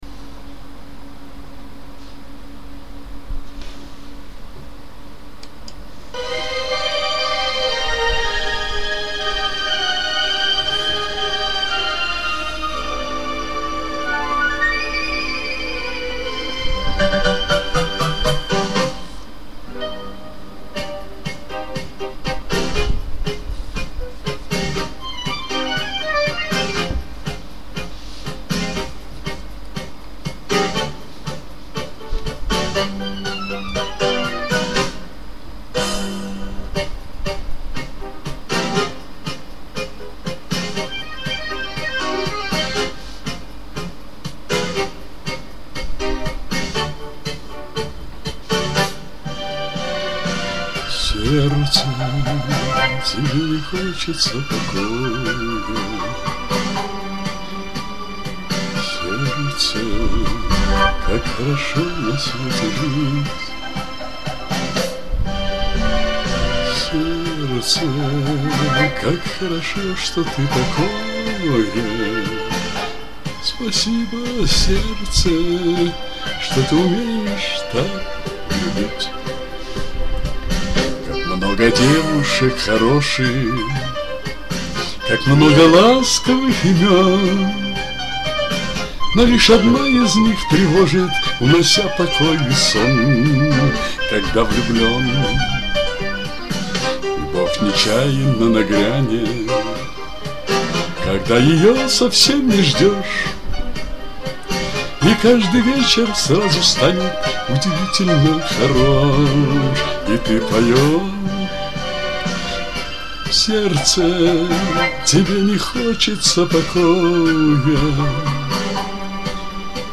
В поединке запрещено оценивать и комментировать тем, у кого менее 5 записей вокала!